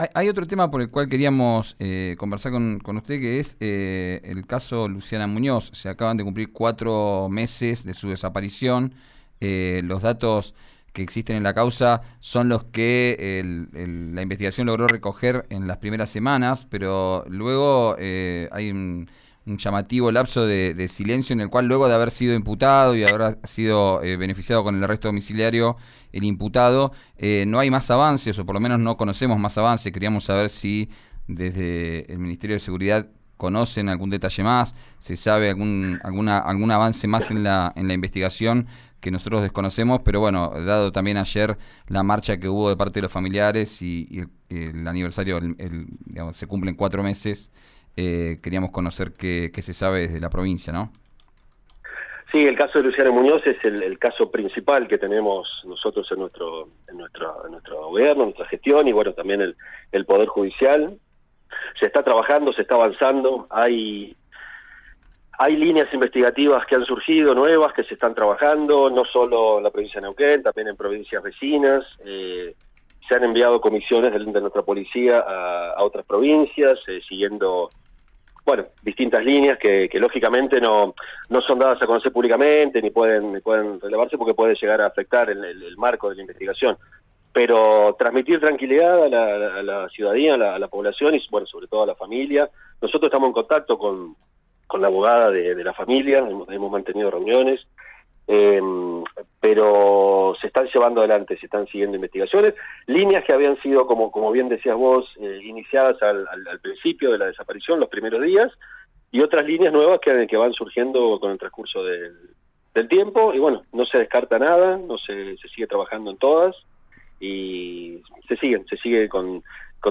El funcionario provincial dialogó con RÍO NEGRO RADIO y respondió preguntas.
Escuchá al titular de la cartera de Seguridad de la provincia, Matías Nicolini en Vos al Aire por RÍO NEGRO RADIO